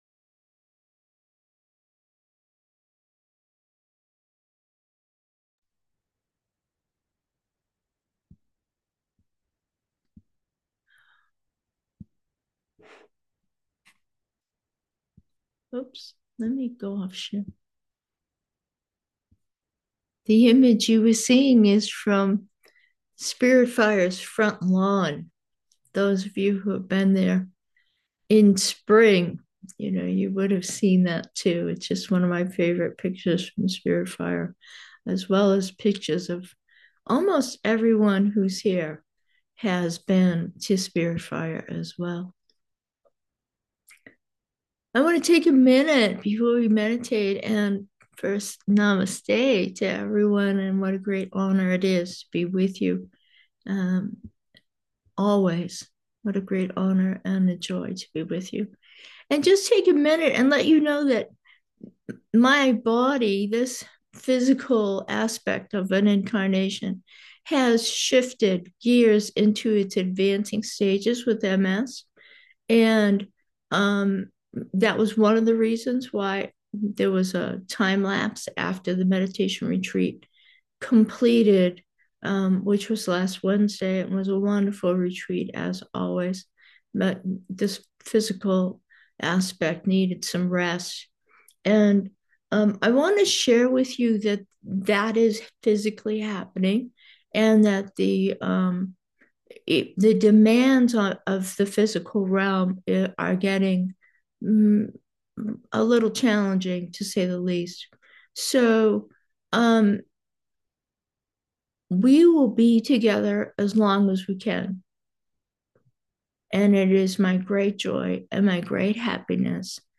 Meditation: peace 1